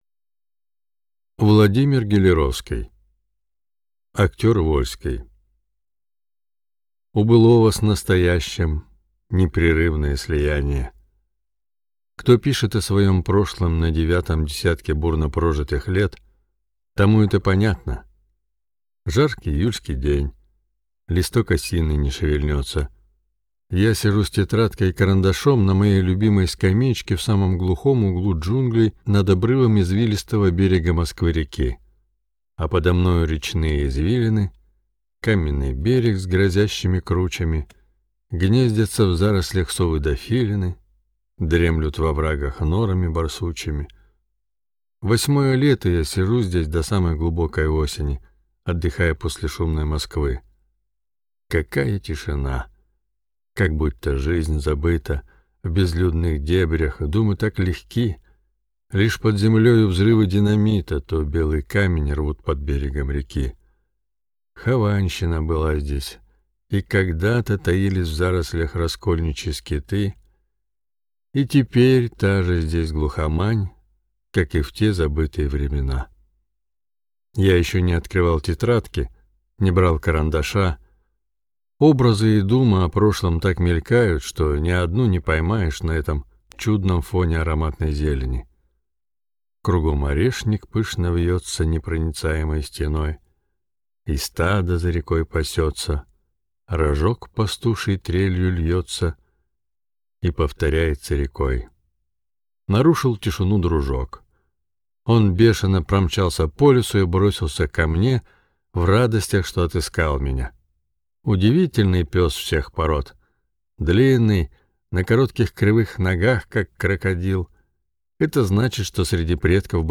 Аудиокнига Актер Вольский | Библиотека аудиокниг